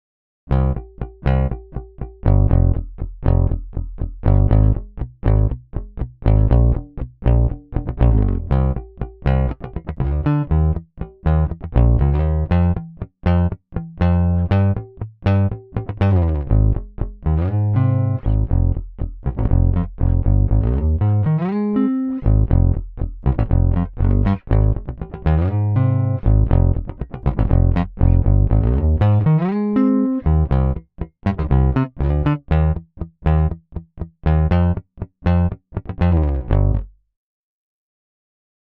清晰细致的中频，是混音的最佳选择
多种拨片演奏和手掌制音奏法
真正的管箱音色，无可比拟的强大力量
声音类别: 适用于刚劲有力的摇滚以及流行音乐的电贝斯